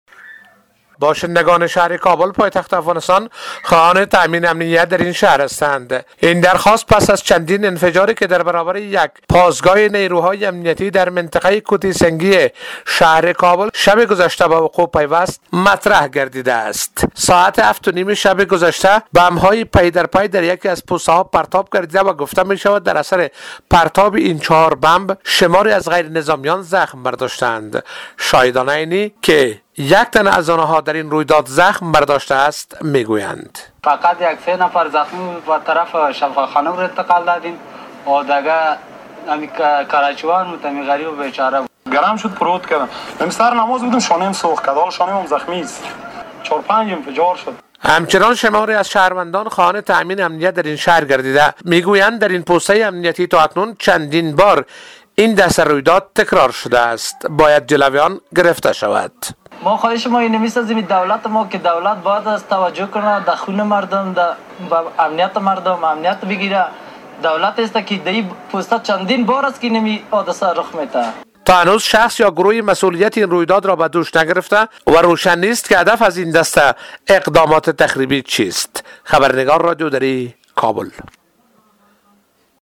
گزارش همکارمان